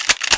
alyx_shotgun_cock1.wav